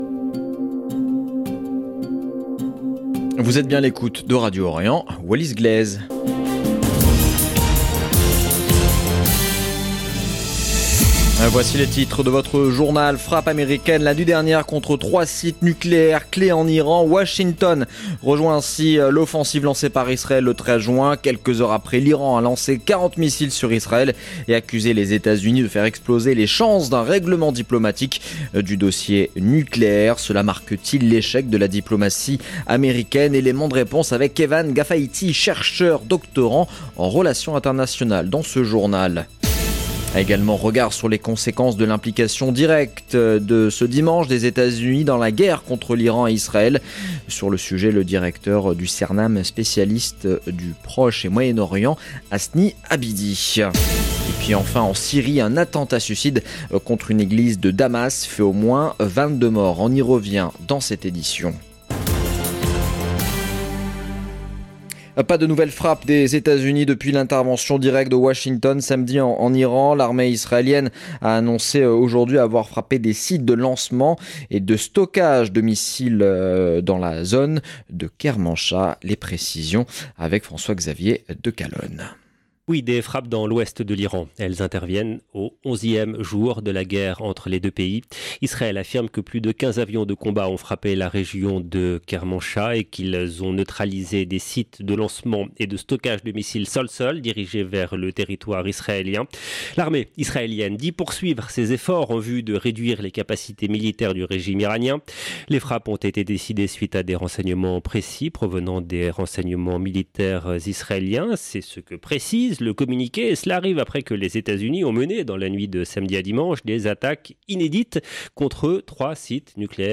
Journal de midi du 23 juin 2025